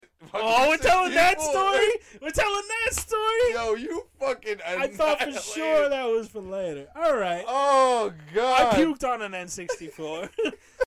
Play Puke 64 - SoundBoardGuy
Play, download and share Puke 64 original sound button!!!!
puked_M37YKXX.mp3